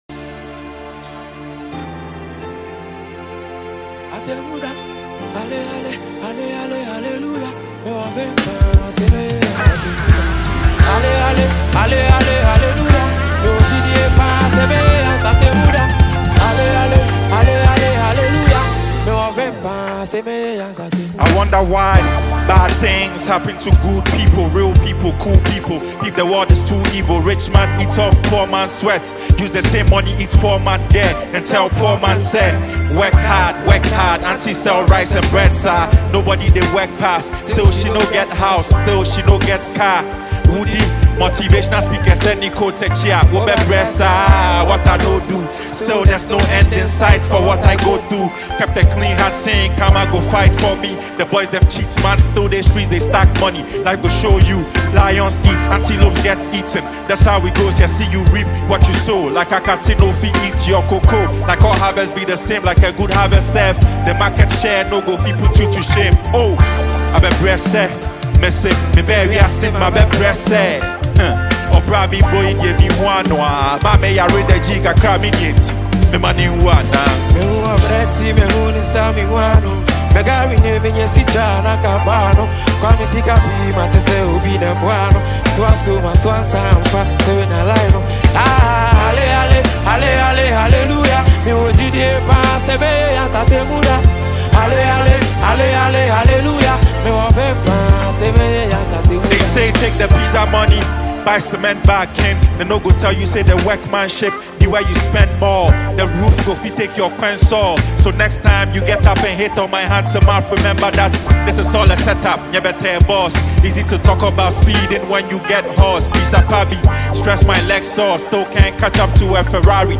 Drums
Keys
Guitar
Bass